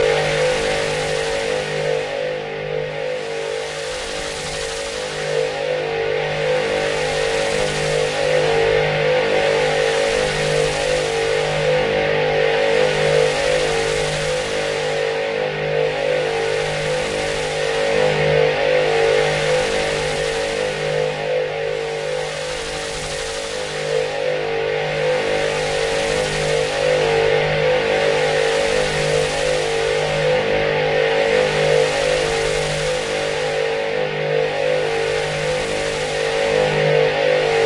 它在背景中播放，有点像《星球大战》中的光剑嗡嗡声。 准确的循环样本。
Tag: 背景下 能源 未来 循环 加入影片箱 科幻 声音设计 色调 武器